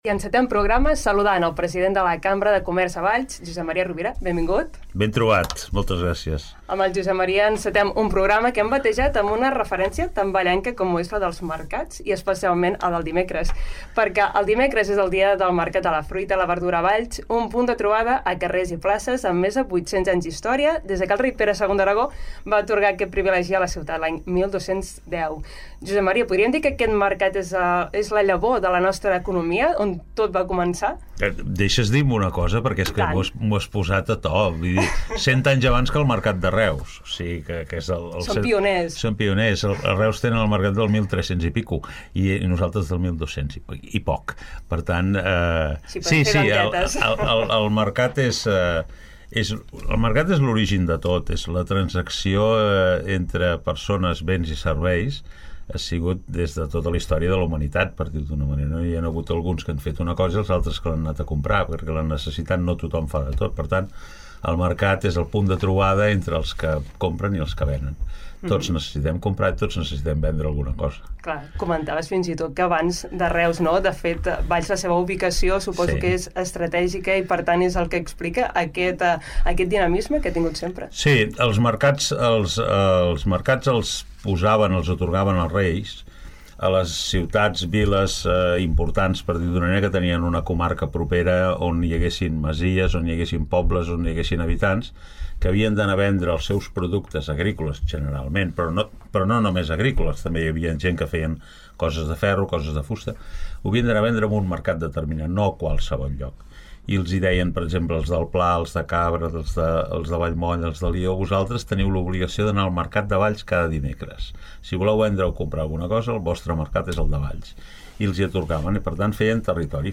Nova entrevista a Ràdio Ciutat de Valls.